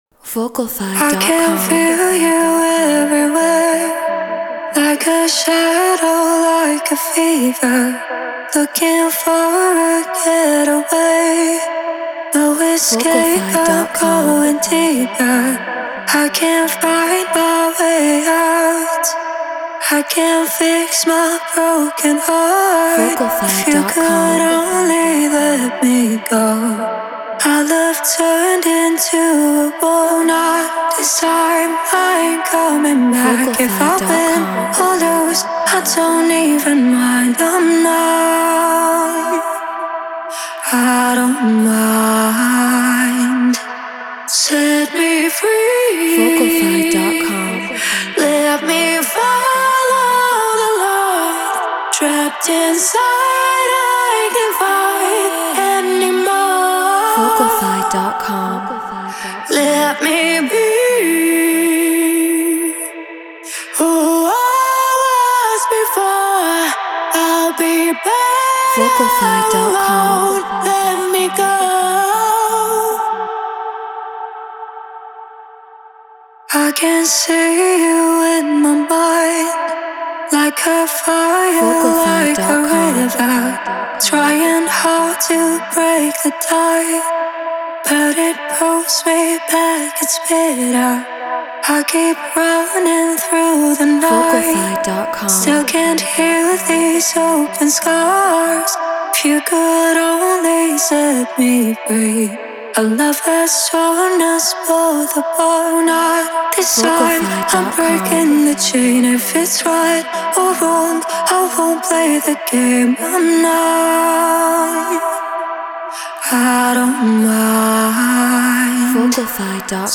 House 127 BPM Emin
Treated Room